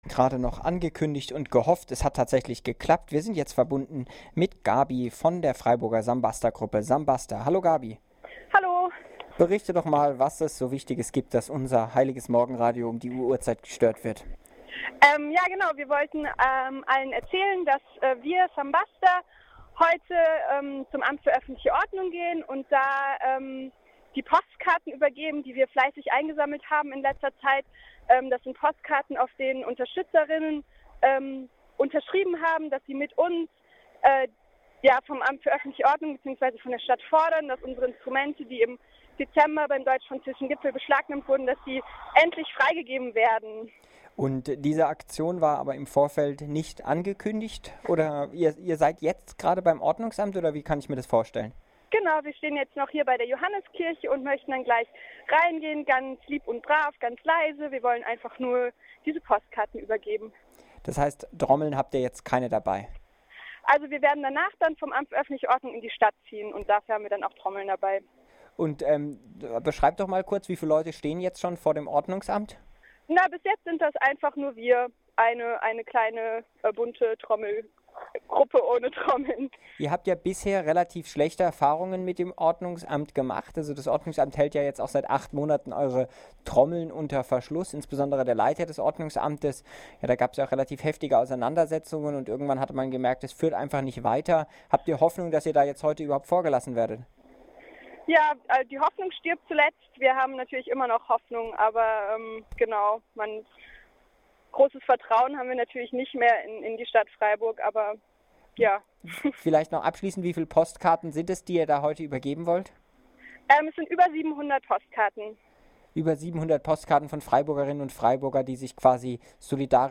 In einem Live-Interview